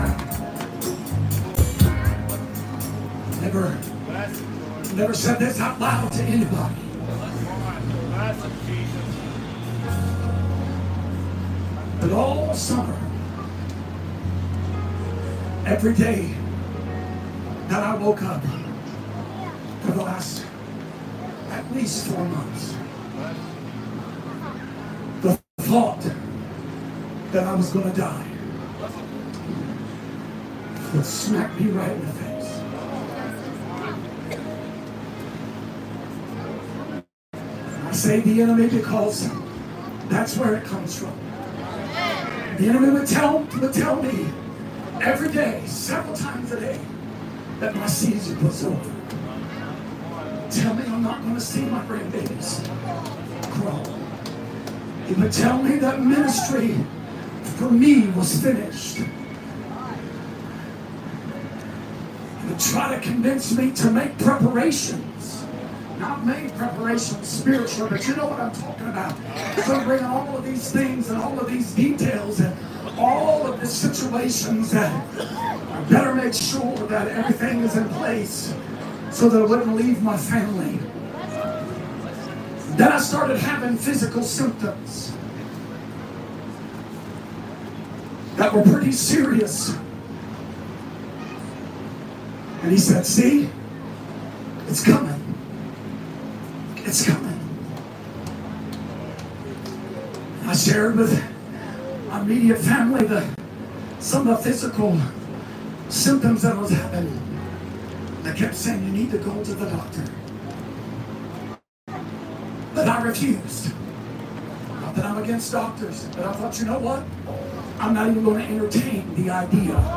Fall Camp Meeting (2024) Current Sermon